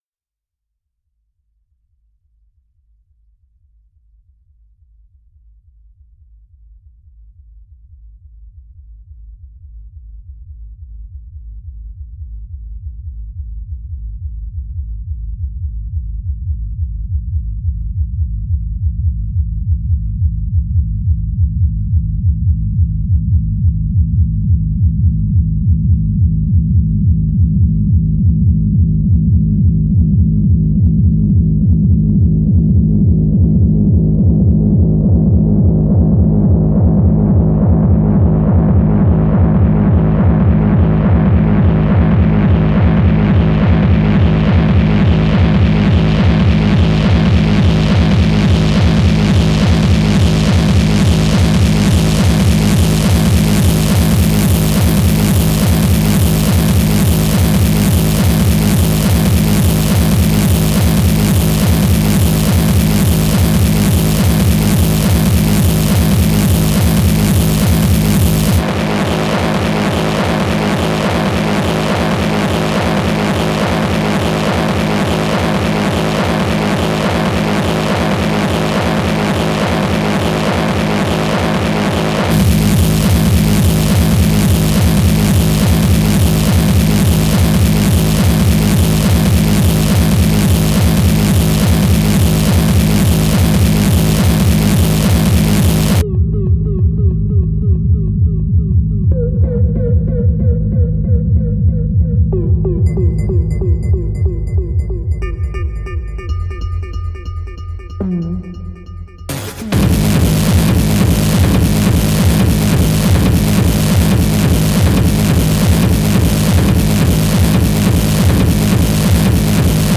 (braindamage/rythmic noise/psychedelic)